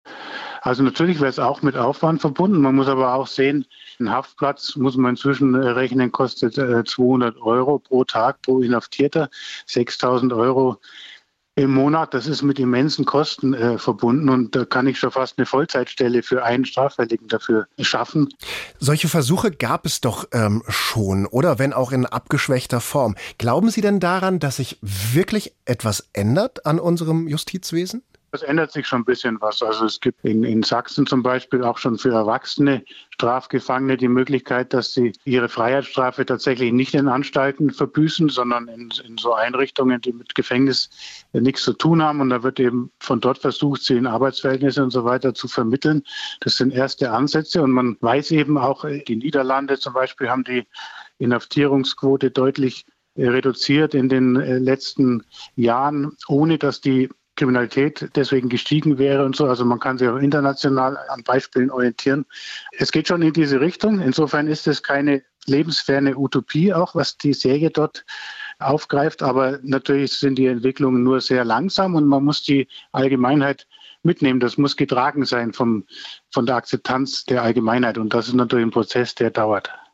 SWR3 Interview